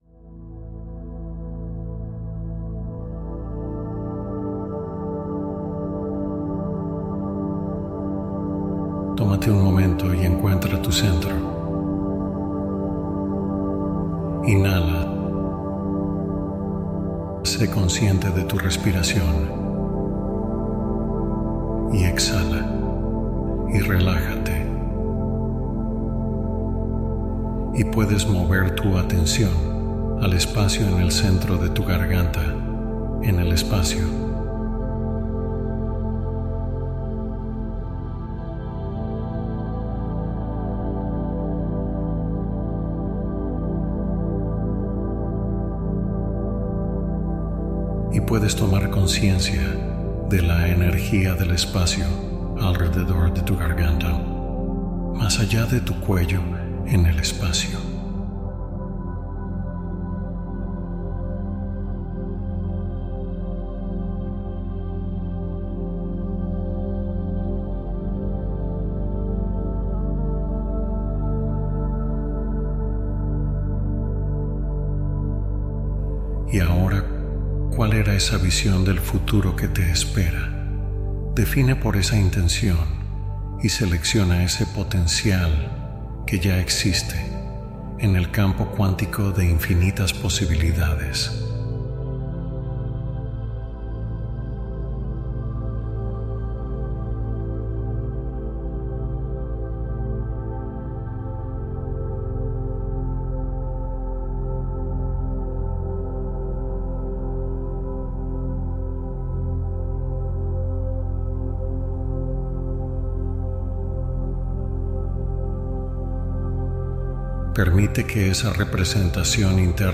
Prepararte para el cambio: una meditación de apertura consciente